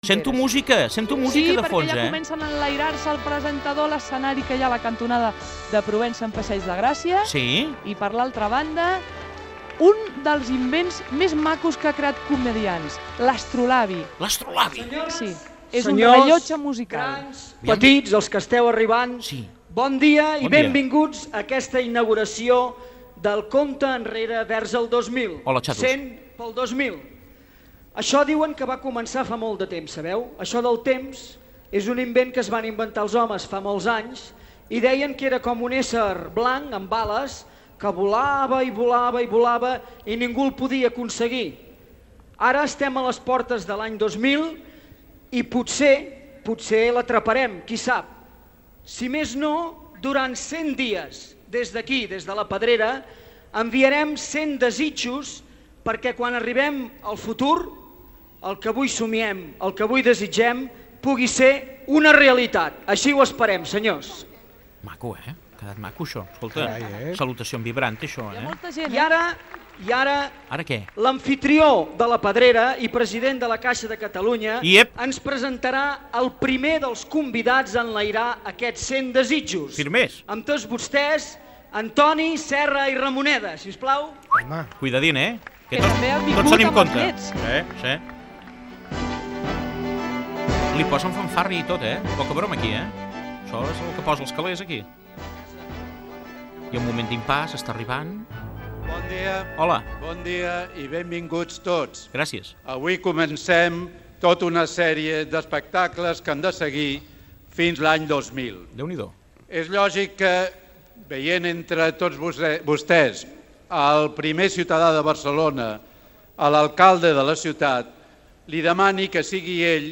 Especial emès quan faltaven 100 dies per l'inici de l'any 2000. Primer dia de la sèrie d'espectacles diaris interpretats pel grup Comediants a la Pedrera de la ciutat de Barcelona.
Entreteniment
Fragment extret de l'arxiu sonor de COM Ràdio